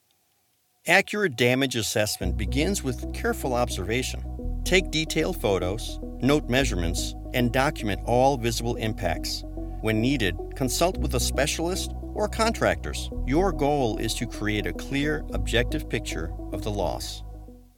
His delivery is warm, steady, and approachable, making even complex material feel manageable.
E- Learning Demos
corporate explainer professional clear elearning